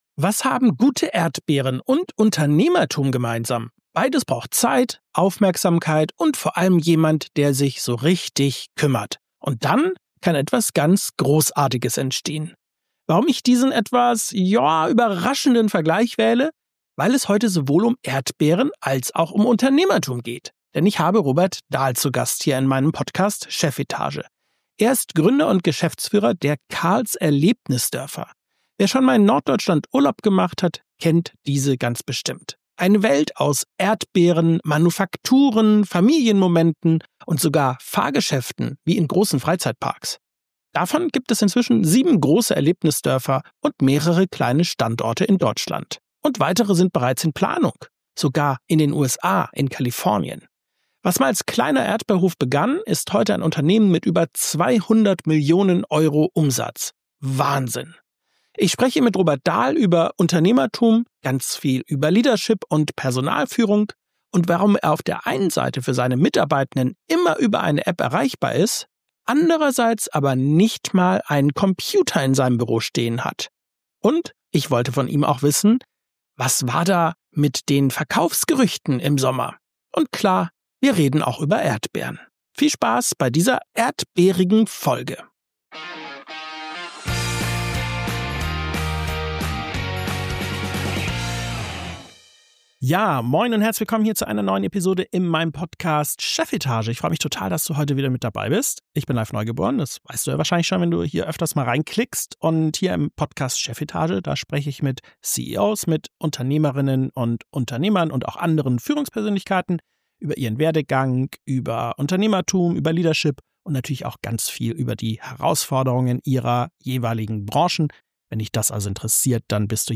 90 ~ Chefetage - CEOs, Unternehmer und Führungskräfte im Gespräch Podcast